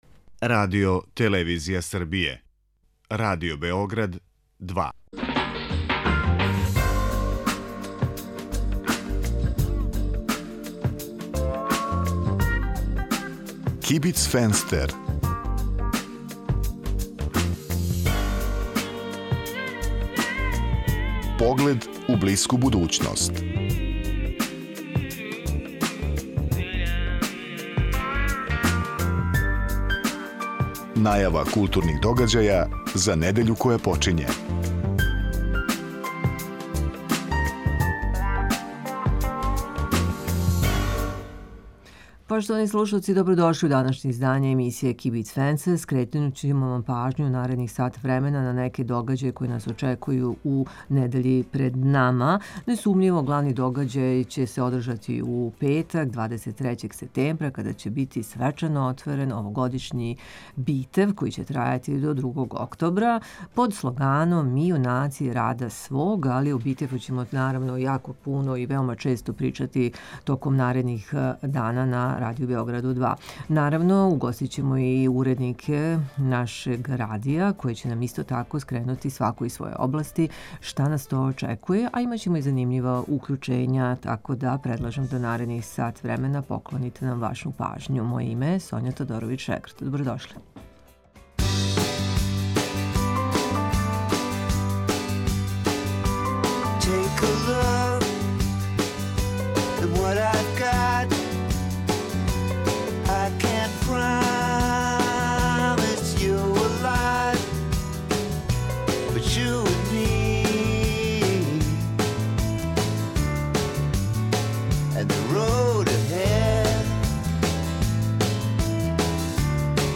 који живи и ради у САД-у. Осим тога чућете избор и препоруку новинара и уредника Радио Београда 2 из догађаја у култури који су у понуди у недељи пред нама.